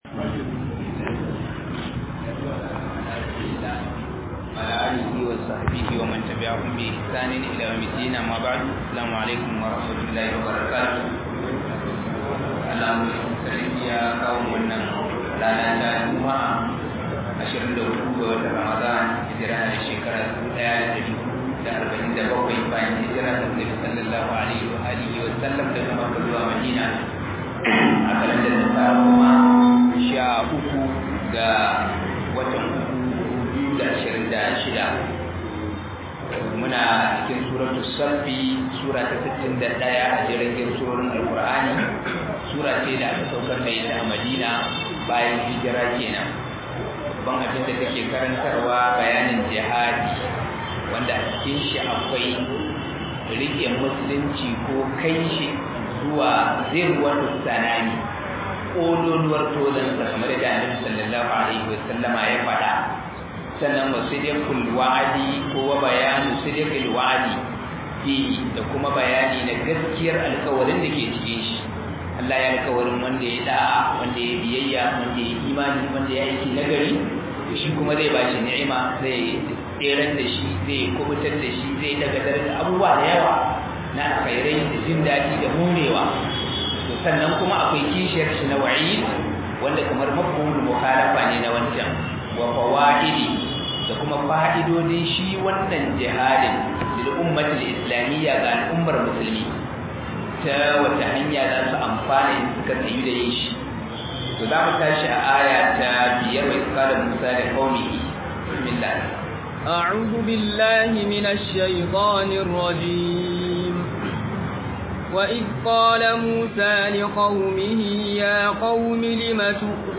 024 Ramadan Tafsir 2026 (Suratul Saffi)